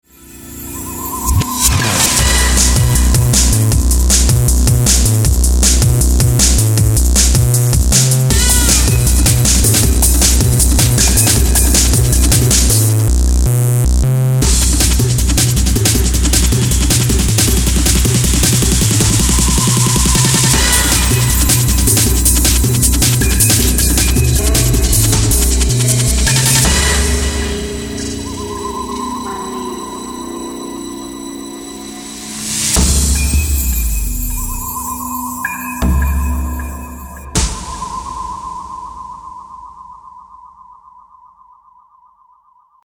Phat bass and bongos